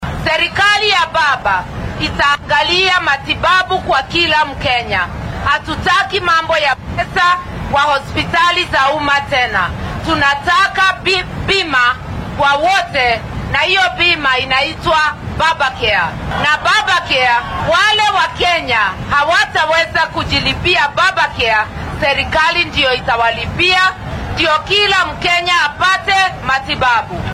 Ku xigeenka musharraxa madaxweyne ee isbeheysiga Azimio La Umoja-One Kenya , Martha Karua ayaa shalay isku soo bax siyaasadeed ku qabatay magaalada Eldoret ee ismaamulka Uasin Gishu.